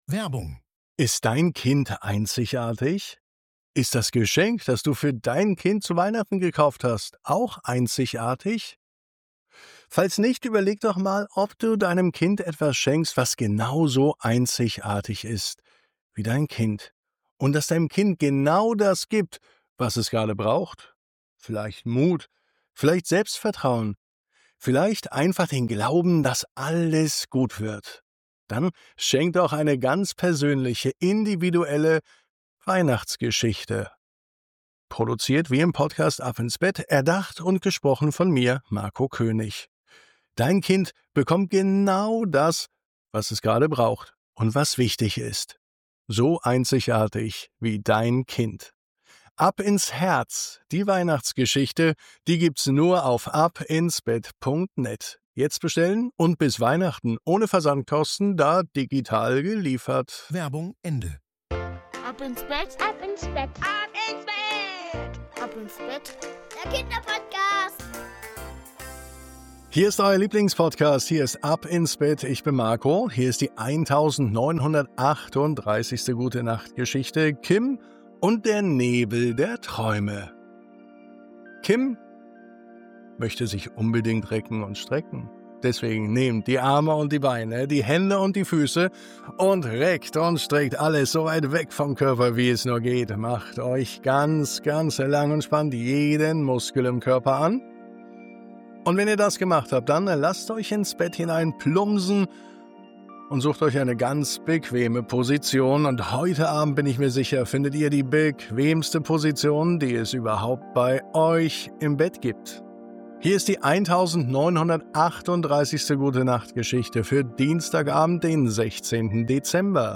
Eine ruhige, liebevolle Gute-Nacht-Geschichte voller Farben, Wärme und leiser Magie.